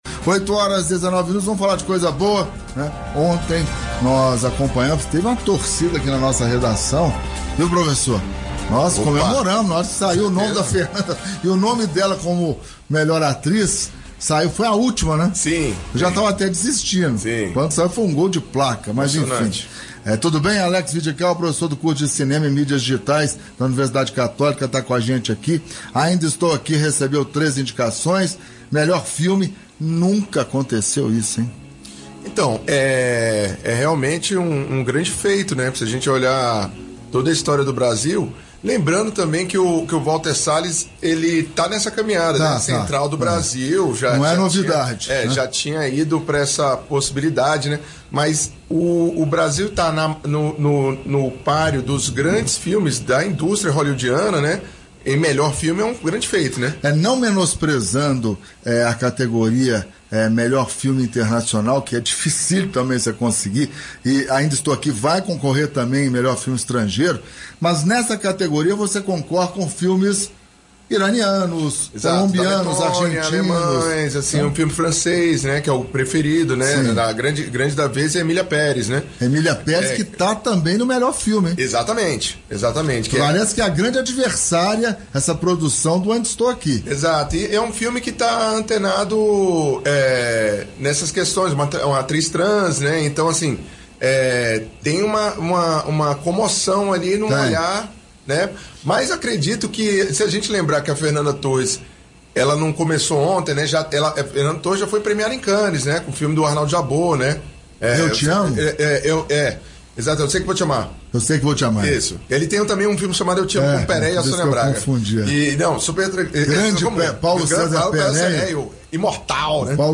deu entrevista à Rádio Metrópoles sobre as indicações do filme Ainda Estou Aqui, de Walter Salles, ao Oscar. A conversa abordou a qualidade filme e da atuação de Fernanda Torres, além da trajetória do cinema brasileiro na premiação desde O Beijo da Mulher Aranha, em 1986. Ainda Estou Aqui foi indicado ao prêmio de melhor filme, melhor filme estrangeiro e Fernanda Torres a melhor atriz.